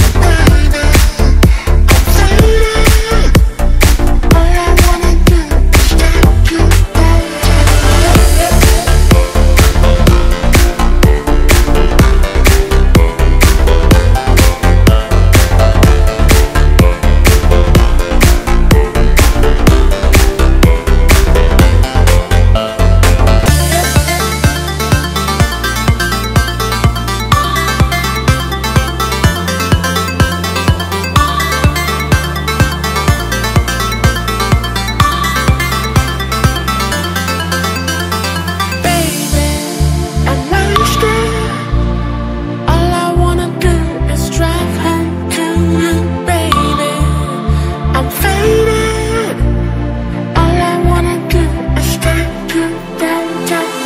• Качество: 320, Stereo
deep house
Electronic
club
электронный голос